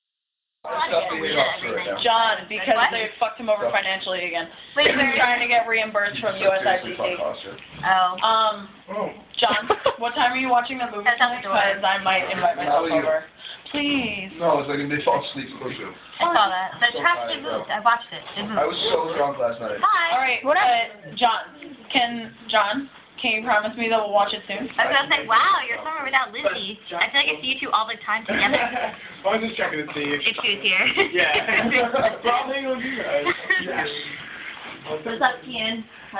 Field Recording #8
Sounds: various voices talking, laughter
Drama-Lounge.mp3